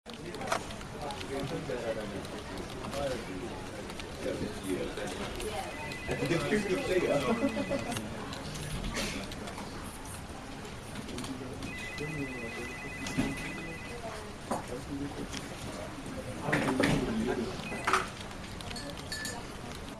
Office Ambience
Office Ambience is a free ambient sound effect available for download in MP3 format.
073_office_ambience.mp3